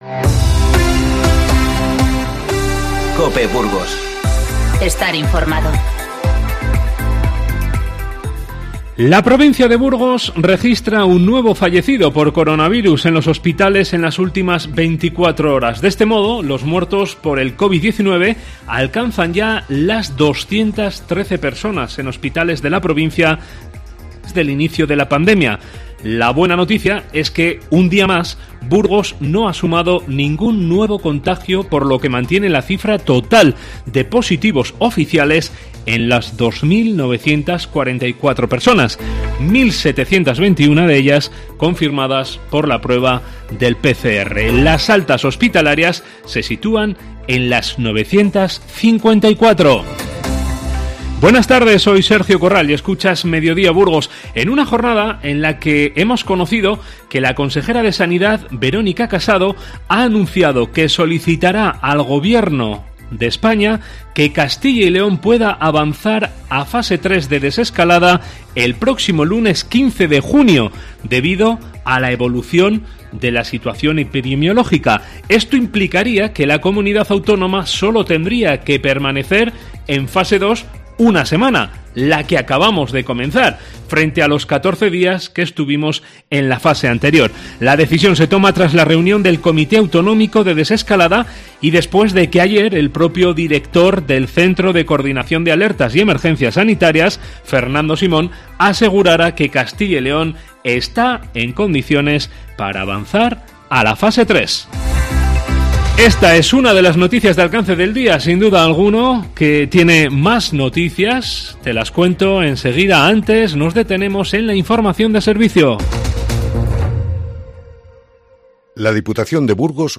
Informativo 9/6